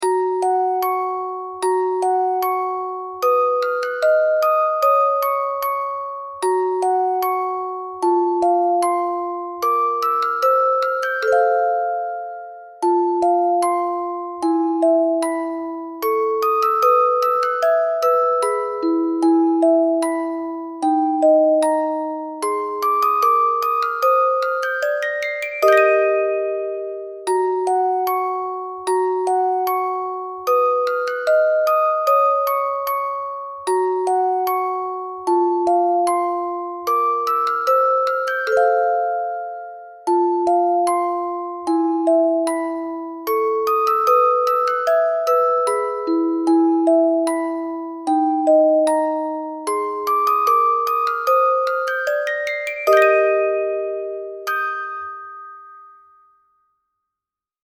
近所の野鳥のさえずりをヒントに、オルゴールにしました。 徐々に調が低くなります。